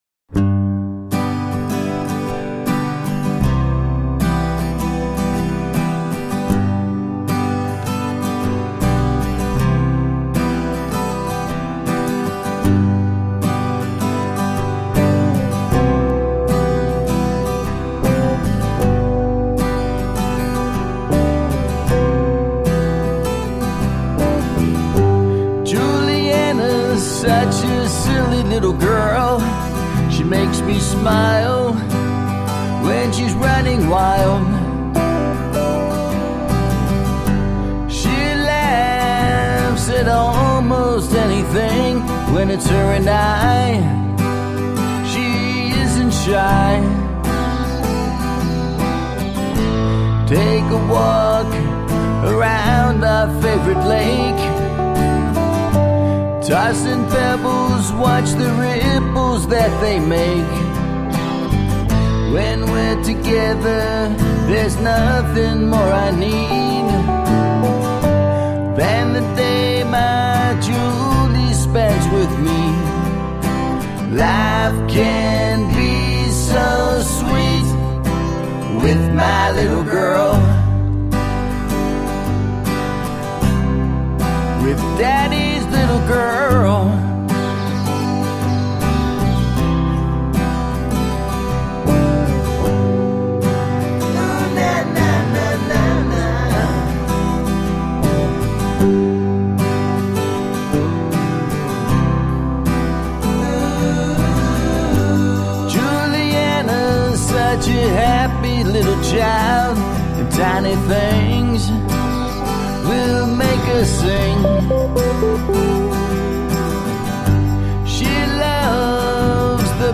Roots rock meets contemporary folk.
The result is a more direct "guitar rock" presentation.
Tagged as: Alt Rock, Folk